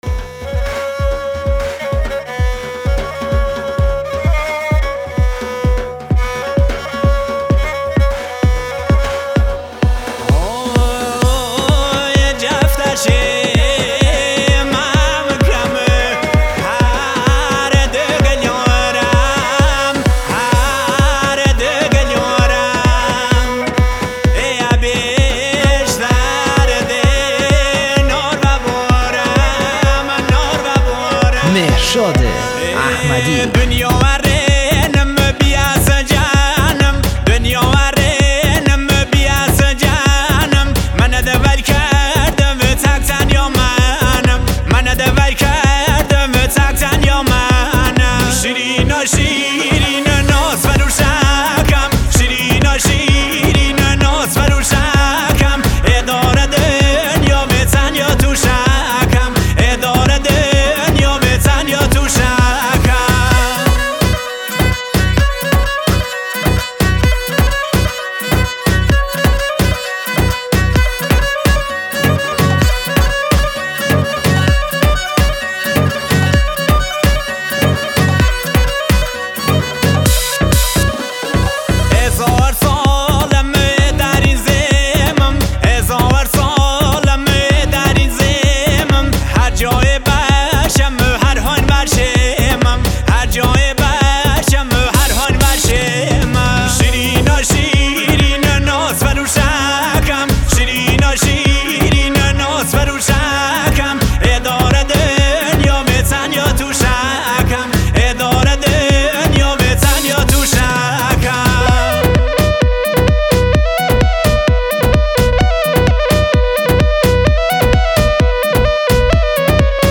آهنگ کردی شاد آهنگ لری